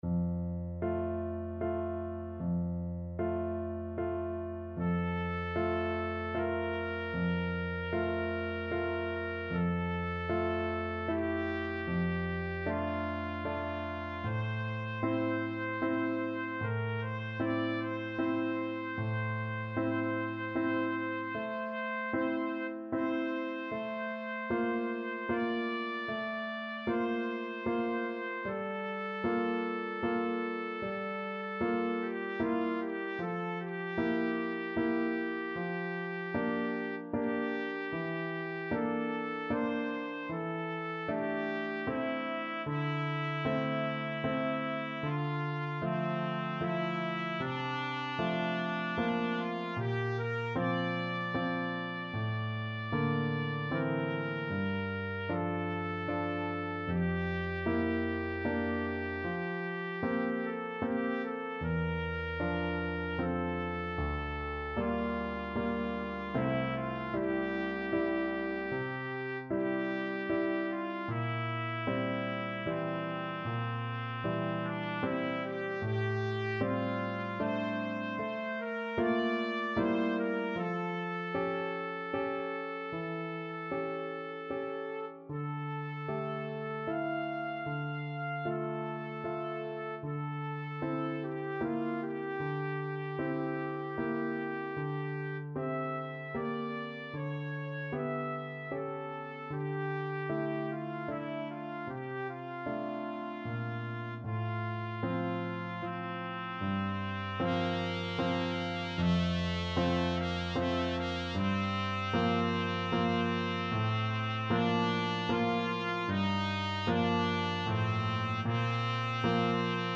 Trumpet
3/4 (View more 3/4 Music)
F major (Sounding Pitch) G major (Trumpet in Bb) (View more F major Music for Trumpet )
Adagio assai =76